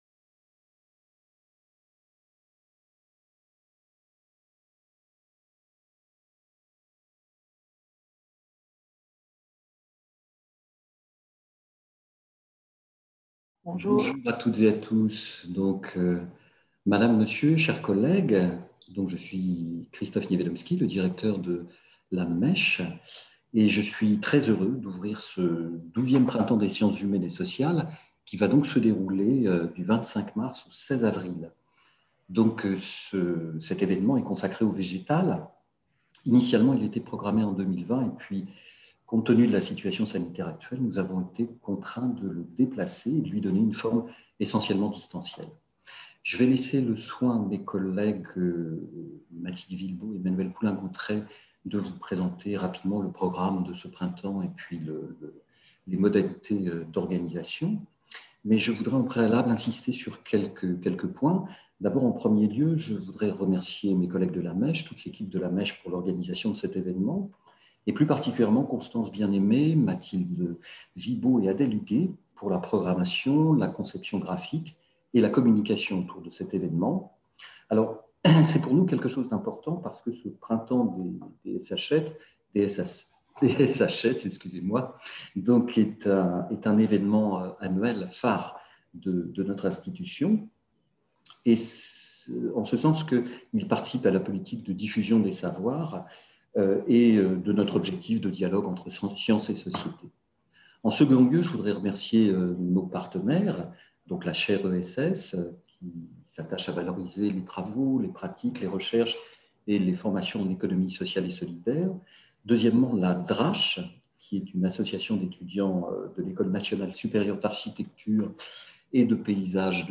Prenant comme point de départ l'ouvrage collectif Puissance du végétal et cinéma animiste. La vitalité révélée par la technique (les presses du réél, 2020), cette conversation portera sur la façon dont le cinéma et les images en mouvement se sont saisis de la vie végétale et du motif des plantes, des arbres ou de la forêt. Conférence